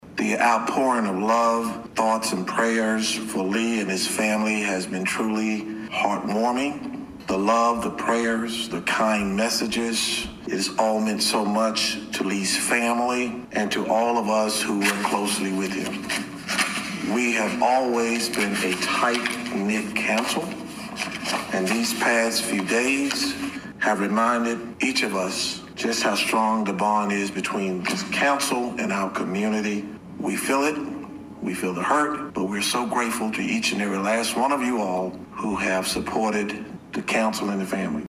Danville City Council held its first meeting on Tuesday night since the attack on Vogler. Mayor Alonzo Jones opened the meeting thanking the City for its support.